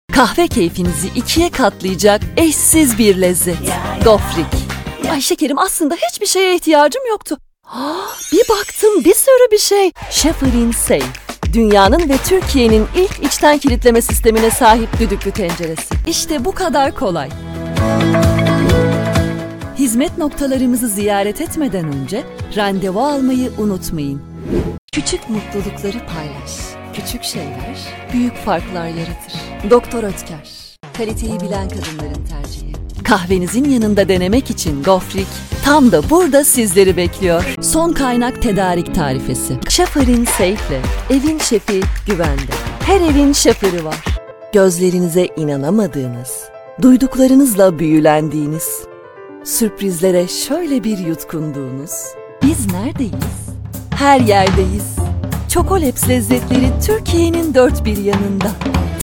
Commercial Demo
I take the recordings in my own professional studio and deliver them in a fast and high quality way. I do reassuring, persuasive, corporate, energetic, cheerful and many tones and styles.
Rode NT1
Acoustic Recording Room